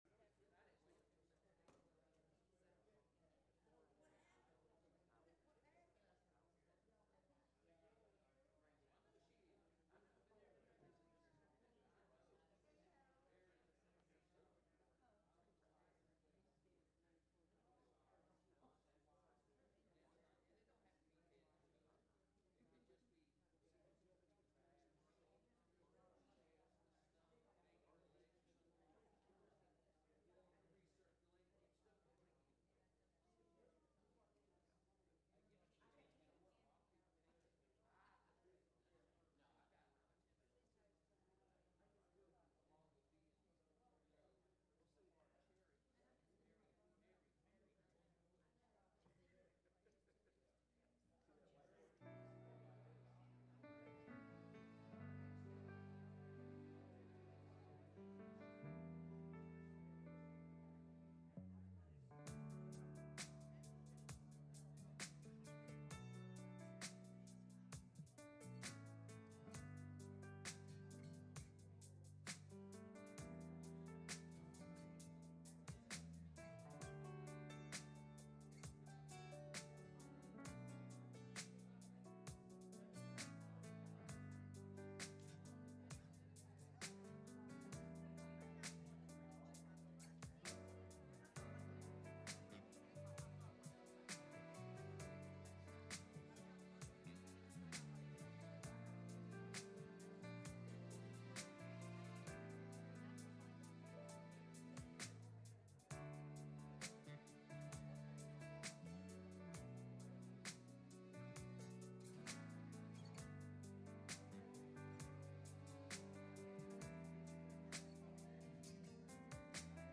Passage: Phillipians 4:15-19 Service Type: Sunday Morning